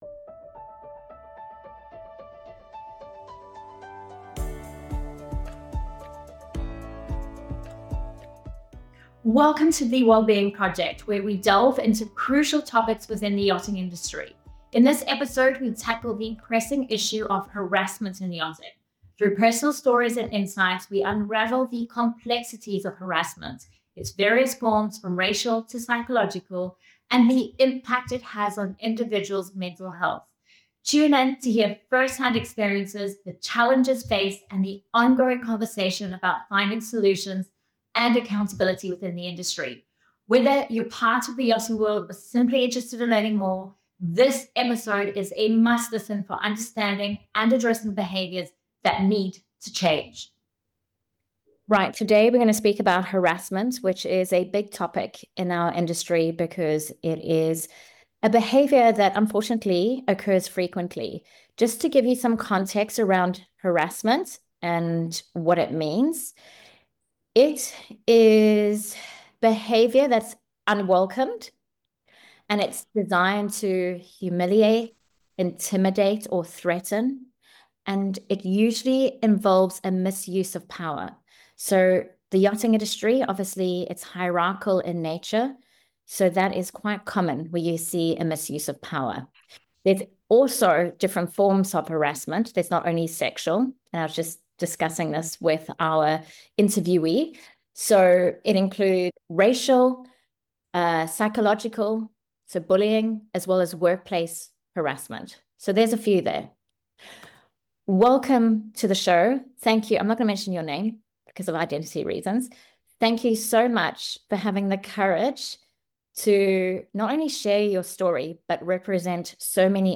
From racial and psychological abuse to the long-term mental health toll, this eye-opening interview pulls back the curtain on the darker side of luxury yachting. 💬 Why is harassment still swept under the deck?